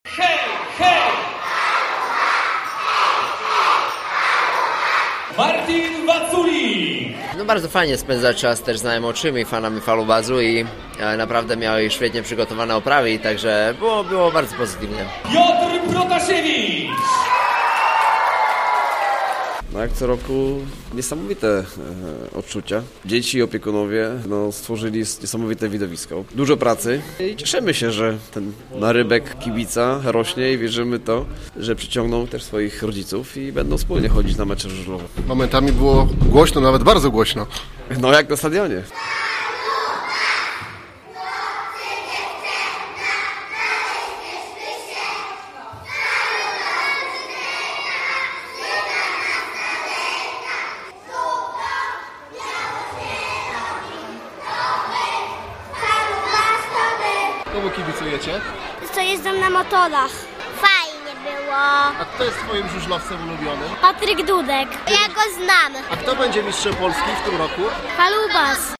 Drugi dzień akcji „60 godzin z Falubazem” rozpoczął się od spotkania zielonogórskich żużlowców z przedszkolakami. W auli Uniwersytetu Zielonogórskiego pojawiło się kilkuset malców, którzy żywiołowo i głośno dopingowali swoją ulubioną drużynę.
Nie zabrakło prawdziwego motocykla żużlowego, szalików, flag z barwami i przede wszystkim głośnego dopingu!